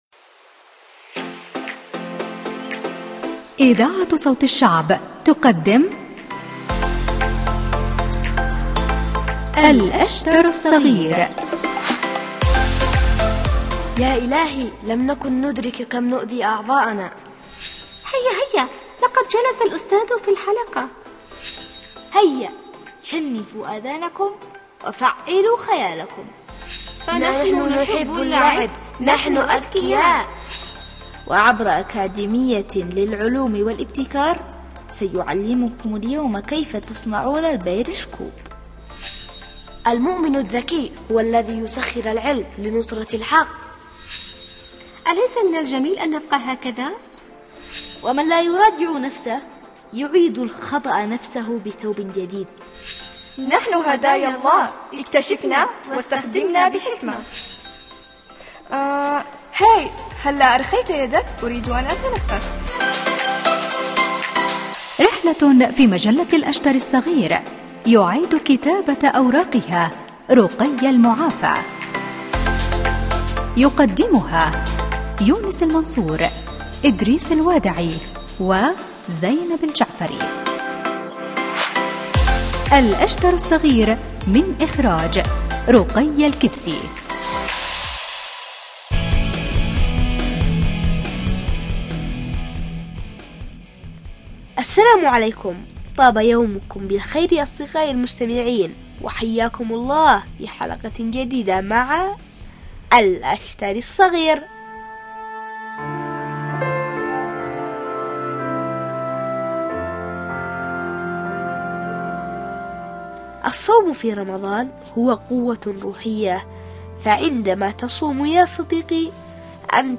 برامج الأطفال